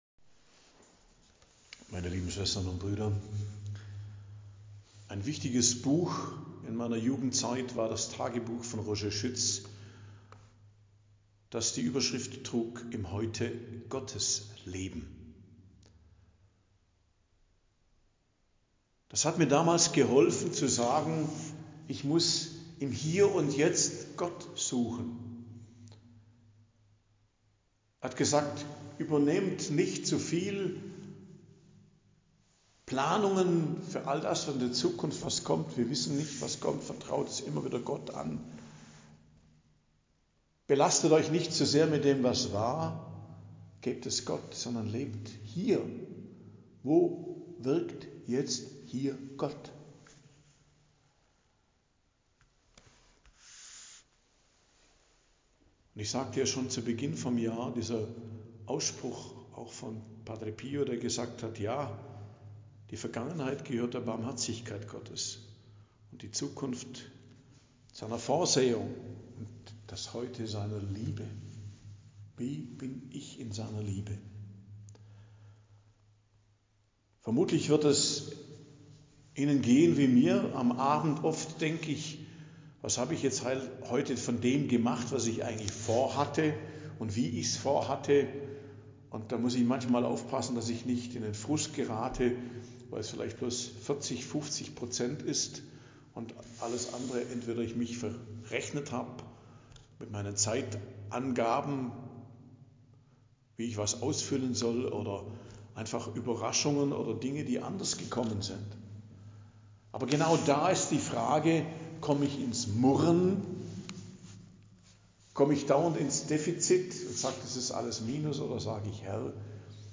Predigt am Donnerstag der 1. Woche i.J. 16.01.2025